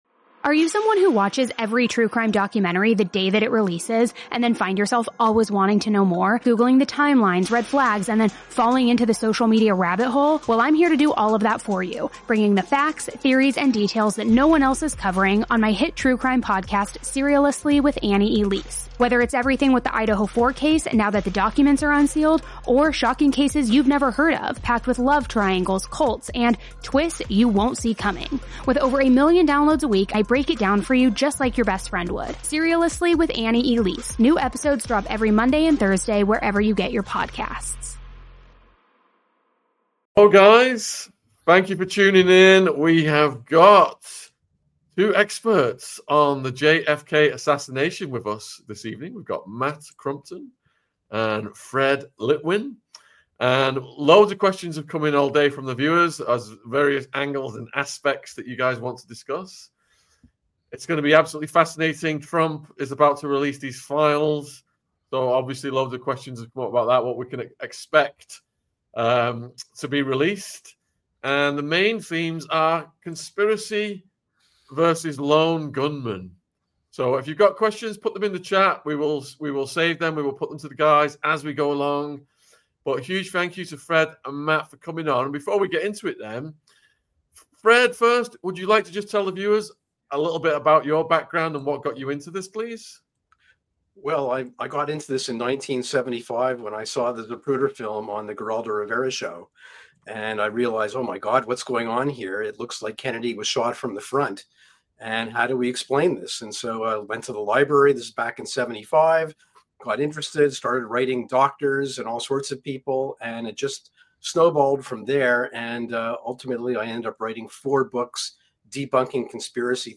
JFK Debate between two diametrically opposed viewpoints.